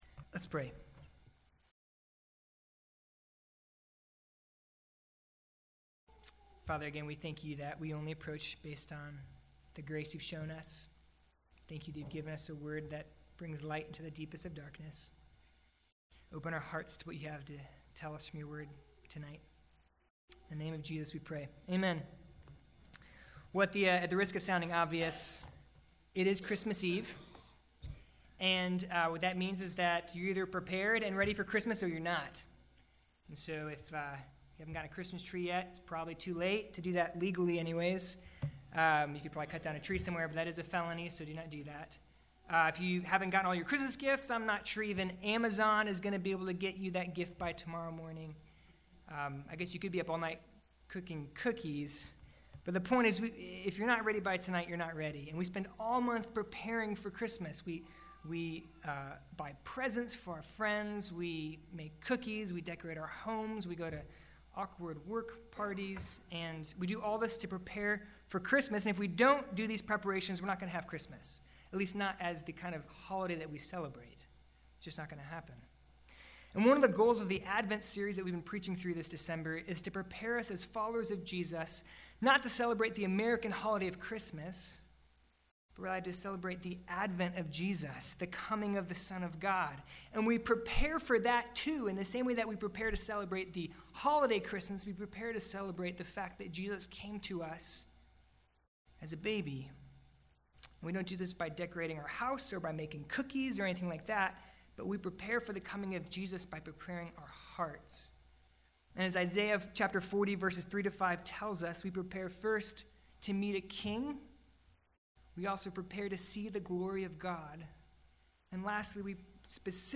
Evening Worship